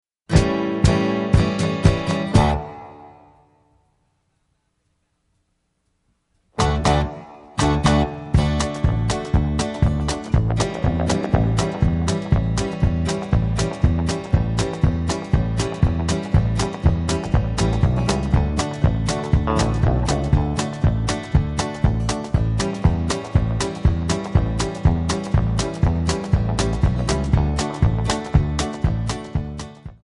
Backing track files: 1960s (842)
Buy Without Backing Vocals